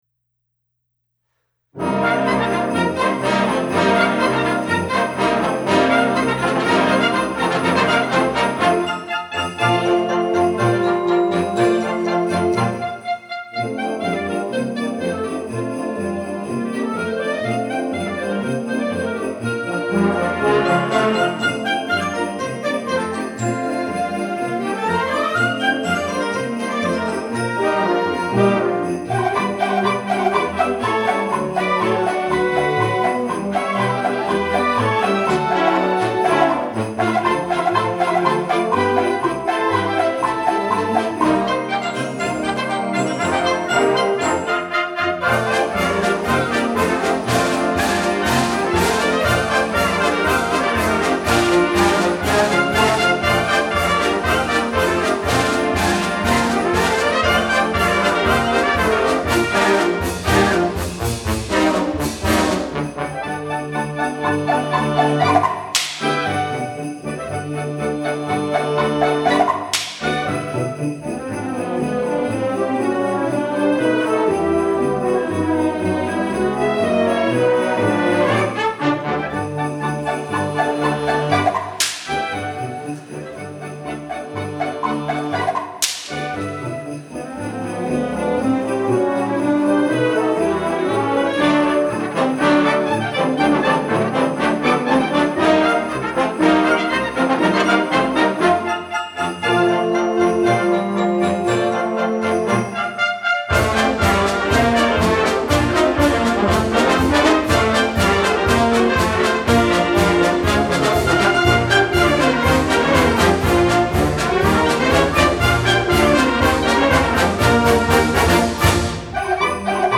Winter Concert
--WIND ENSAMBLE--